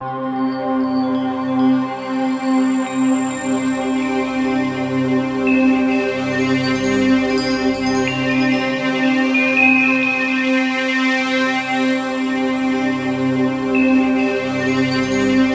TUBULARC3.-L.wav